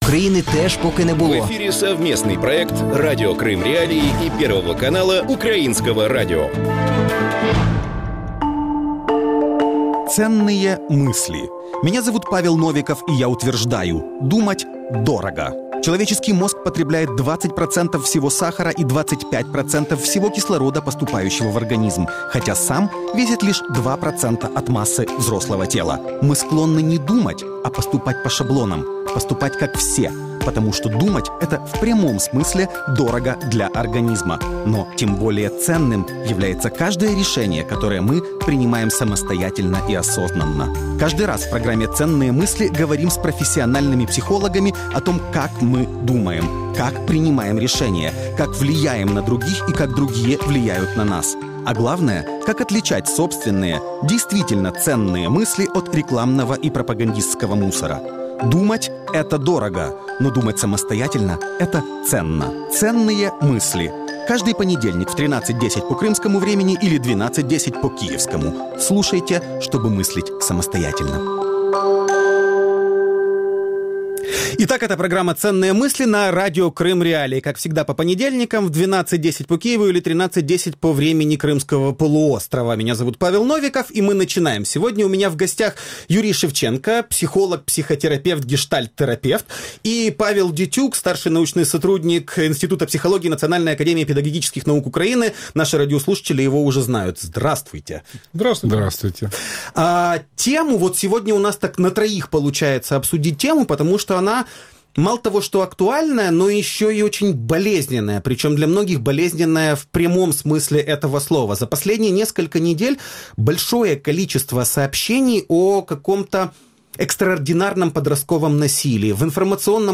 Об этом – в проекте «Дневное шоу» в эфире Радио Крым.Реалии с 12:10 до 12:40.